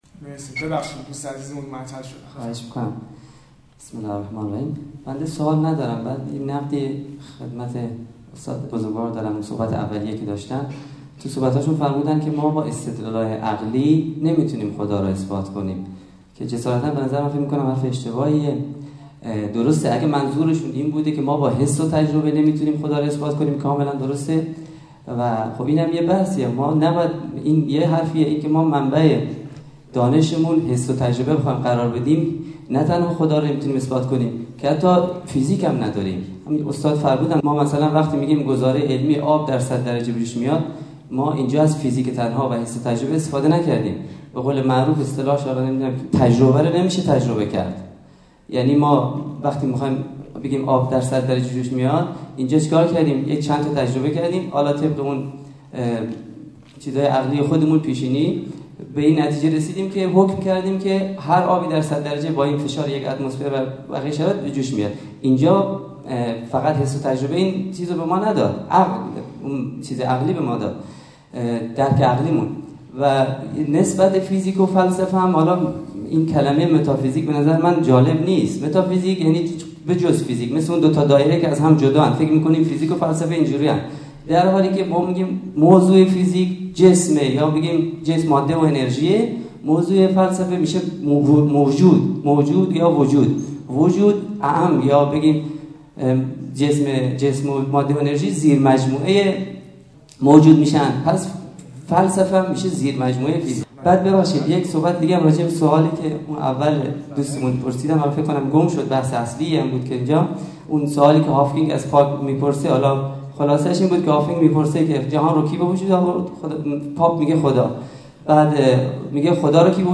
تریبون آزاد دانشجویی با موضوع الحاد جدید2.mp3